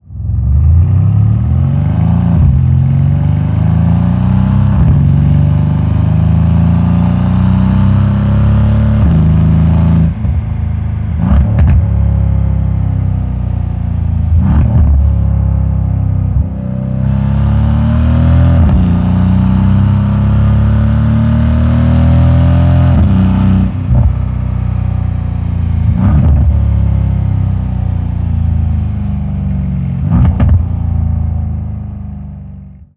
Realistischer Klang: Erleben Sie echten Auspuffsound per Knopfdruck, ein- und ausschaltbar nach Belieben.